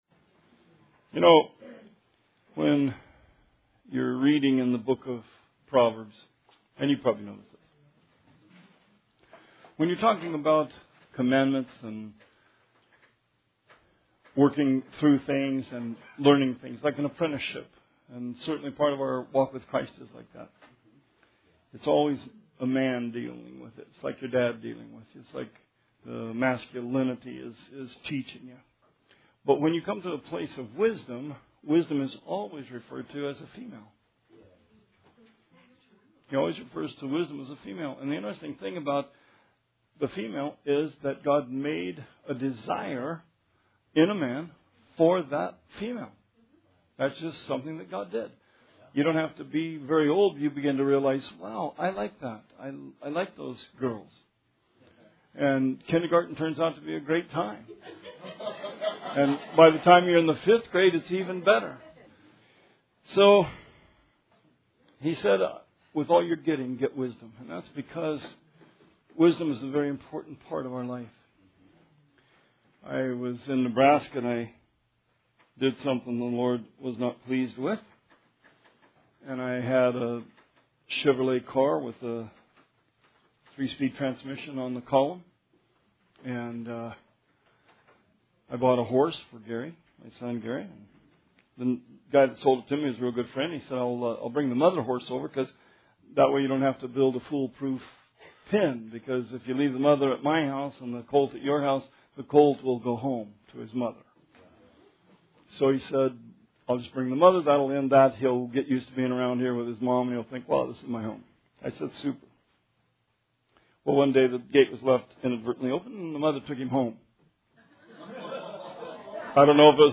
Sermon 5/7/17